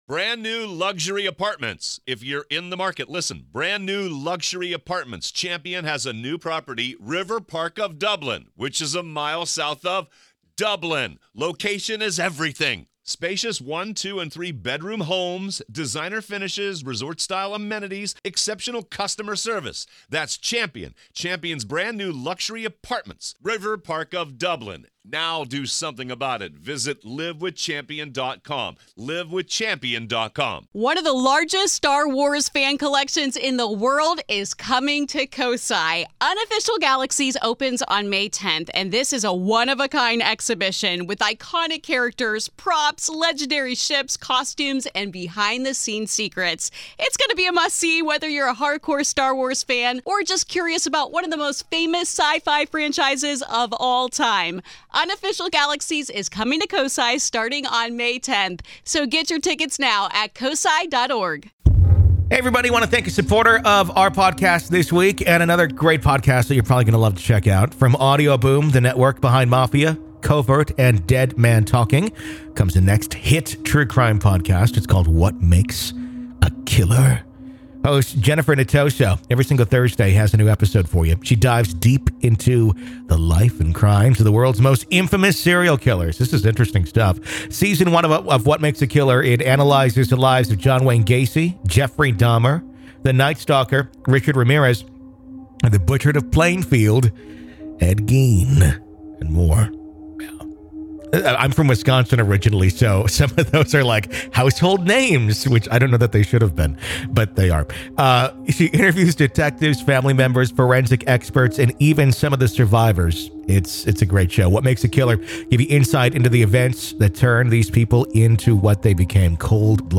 Featuring interviews with family members, forensic experts, law enforcement, and witnesses.